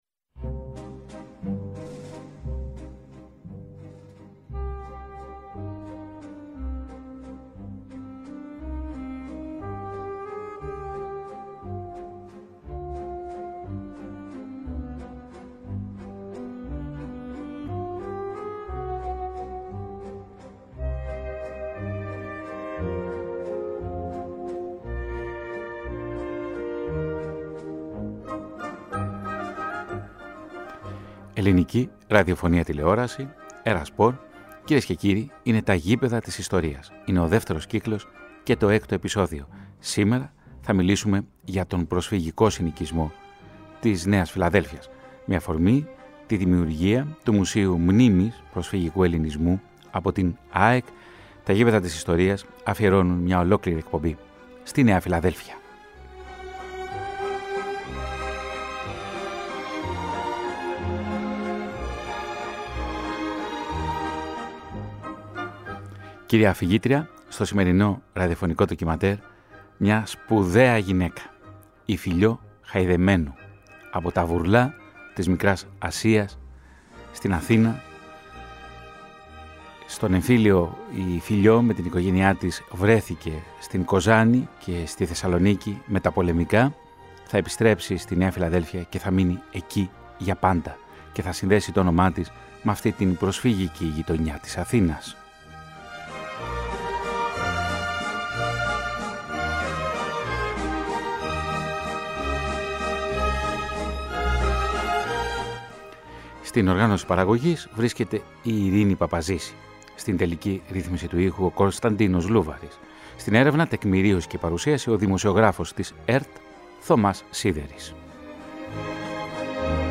Με αφορμή τα εγκαίνια του Μουσείου Προσφυγικού Ελληνισμού, που βρίσκεται στο γήπεδο της ΑΕΚ, η σειρά ραδιοφωνικών ντοκιμαντέρ της ΕΡΑ ΣΠΟΡ αφιερώνει το έκτο επεισόδιο του β’ κύκλου στη Νέα Φιλαδέλφεια των προσφύγων.